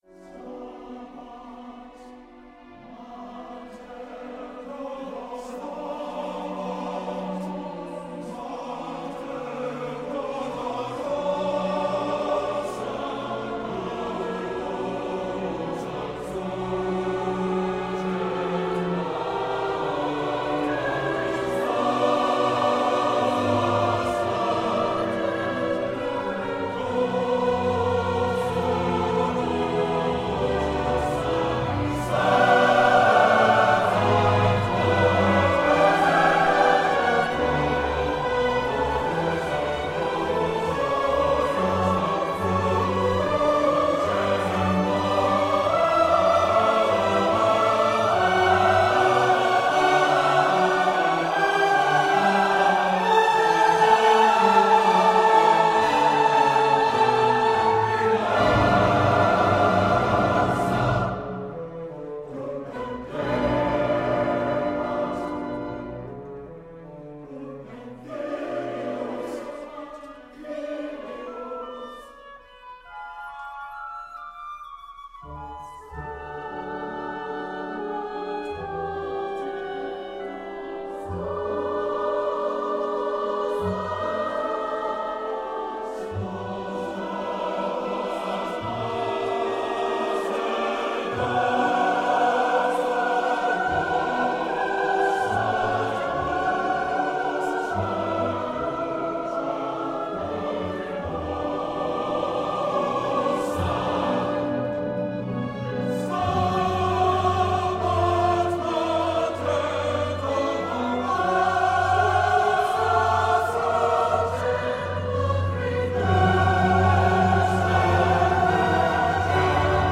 chorus and orchestra
in Sanders Theatre, Harvard University, Cambridge, MA